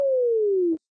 arc_reduce_short2.ogg